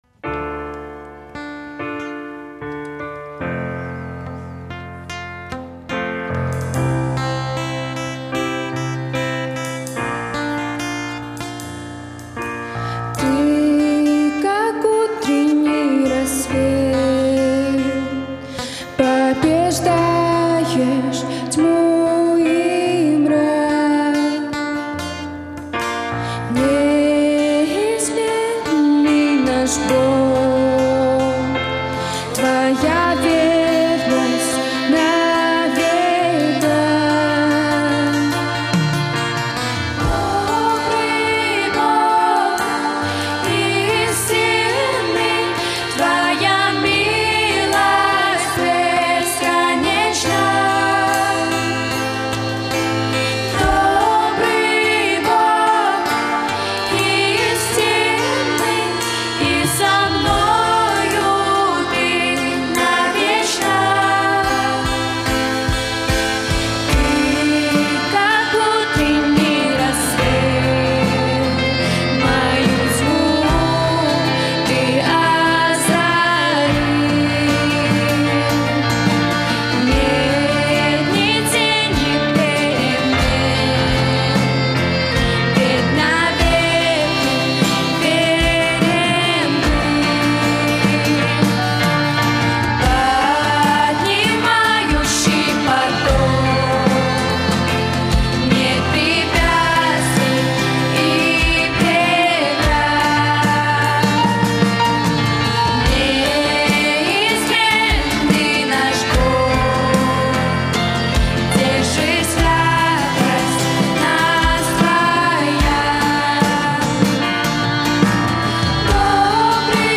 Псальмы гурта
В сентябре 2023 года Бог дал желание и возможность принимать музыкальное участие в церкви на общих служениях в составе небольшой группы.